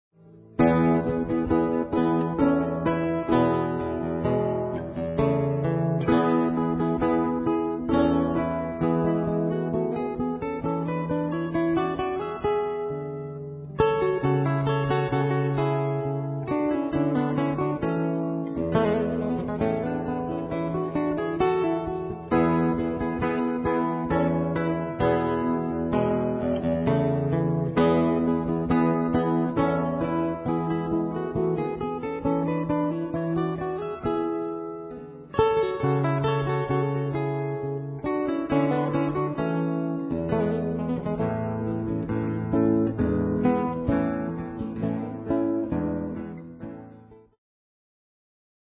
Muramatsu flute
Guitars
Harpsichord